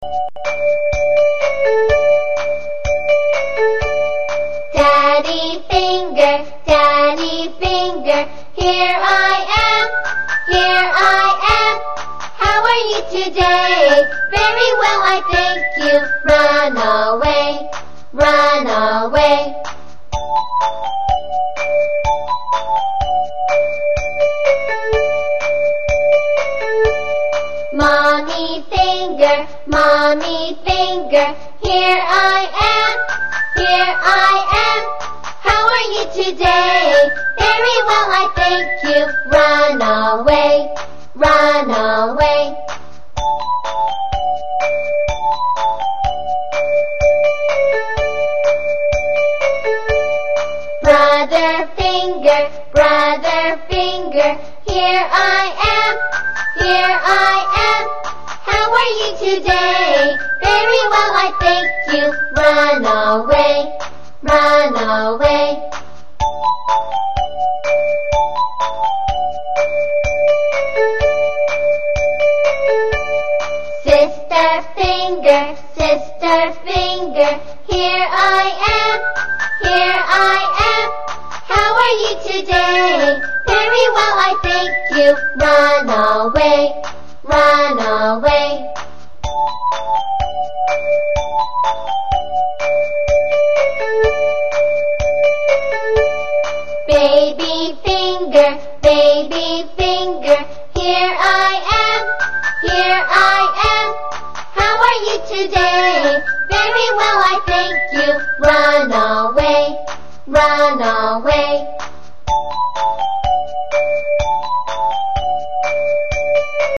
英文儿童歌曲:英文手指谣--Finger family 手指家族 听力文件下载—在线英语听力室